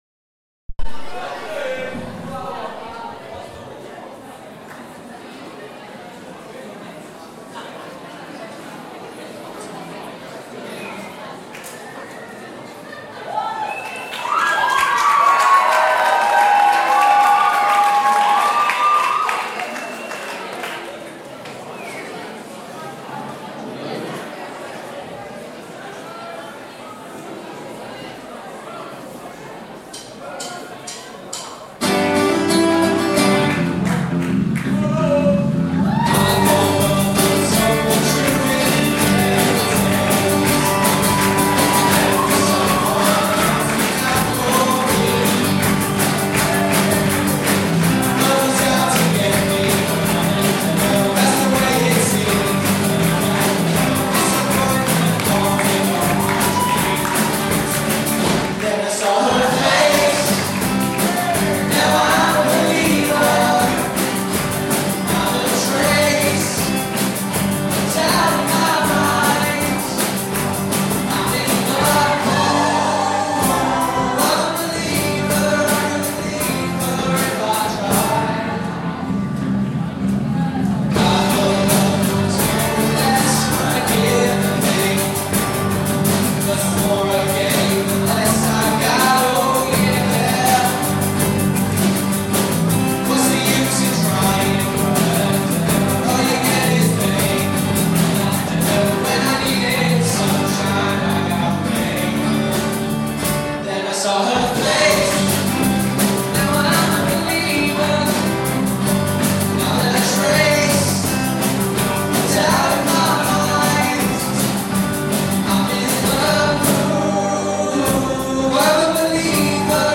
Year 11 awards evening